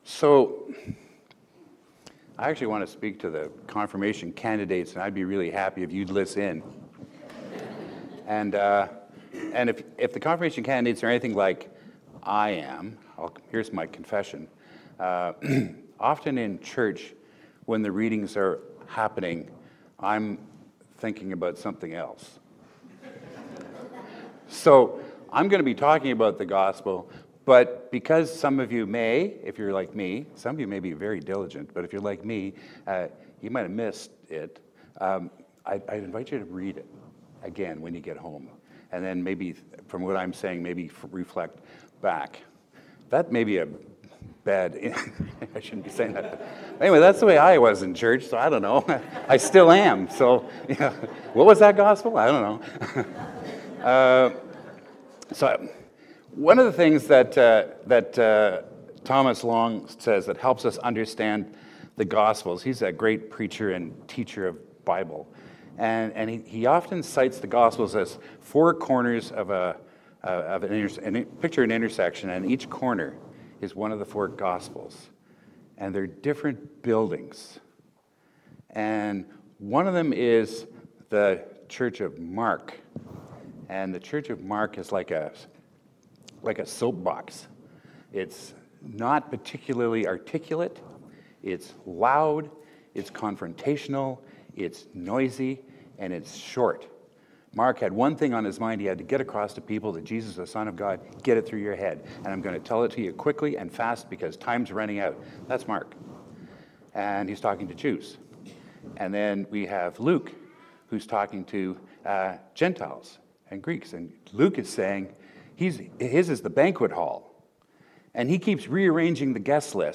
Preached at a Celebration of Confirmations and Reception